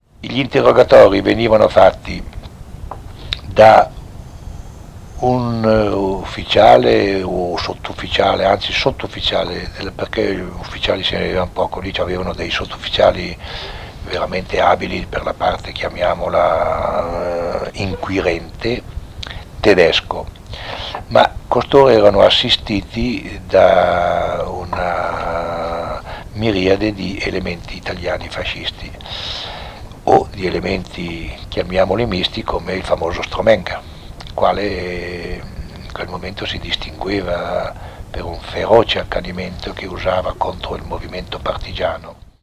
intervistato a Bergamo il 3 febbraio 1977